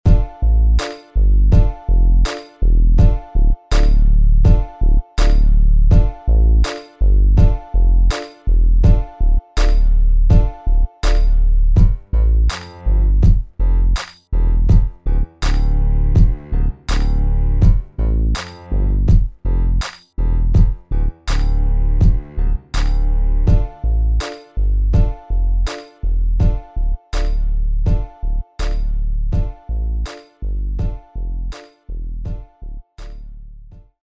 West Coast Rap Beats